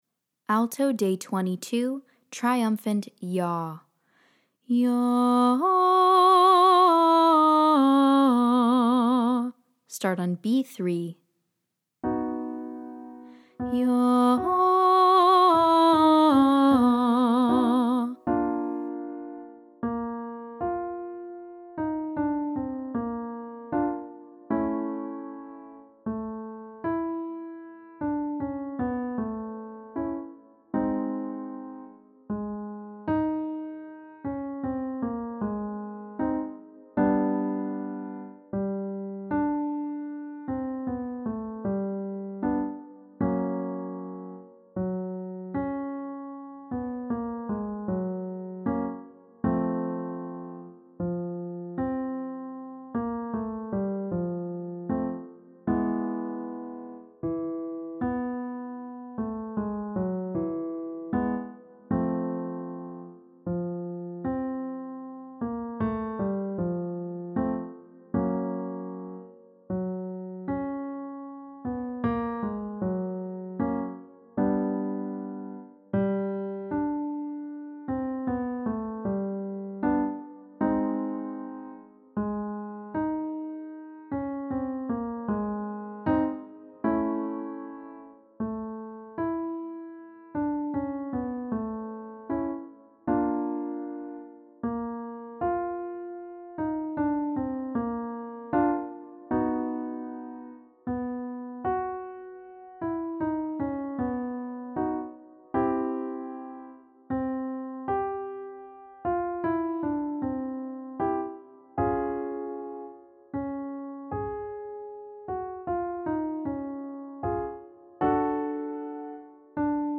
• Exercise: Triumphant YAH (pattern: 1-5-4-3-2-1)
Day 22 - Alto - Triumphant 'YAH' & Vibrato